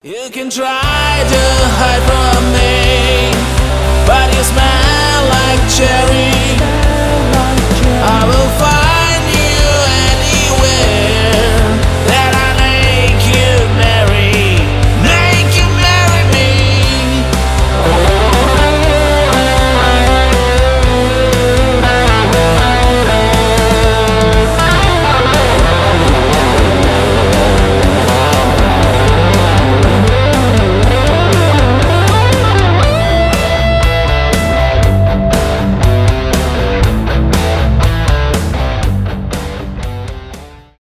art rock , рок , progressive rock
progressive metal